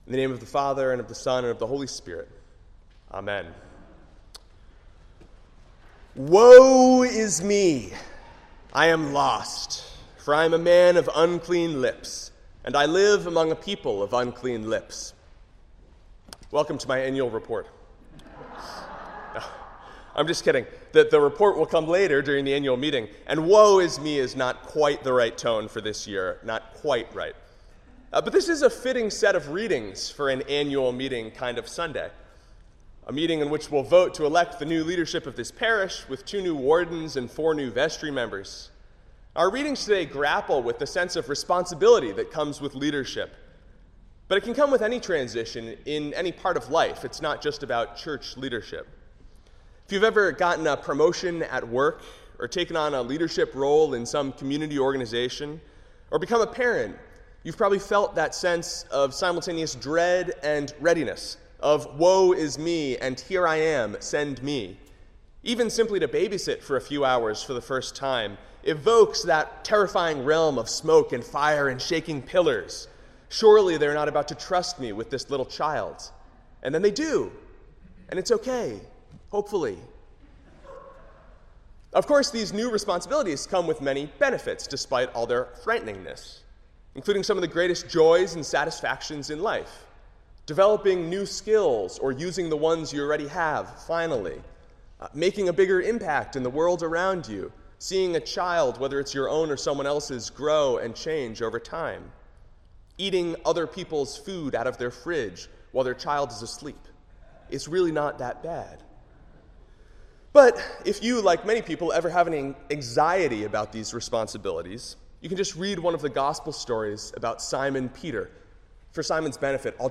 Sermon — February 6, 2022